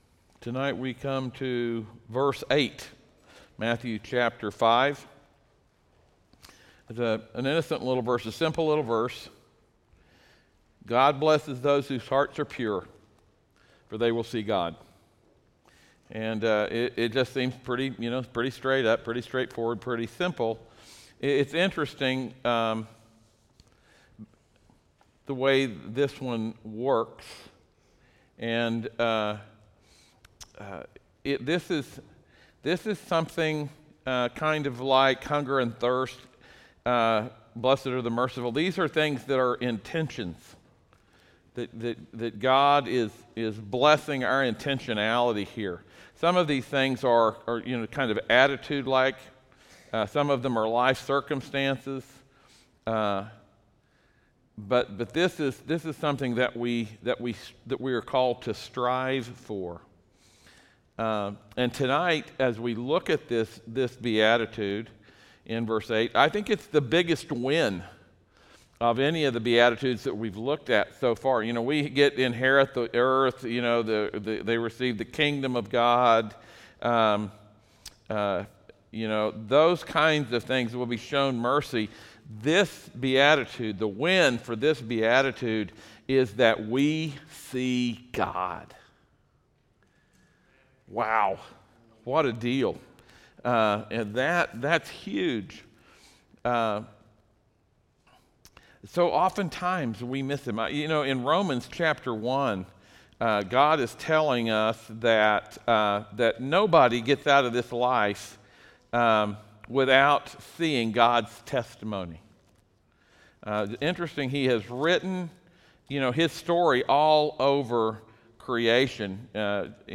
Service Type: audio sermons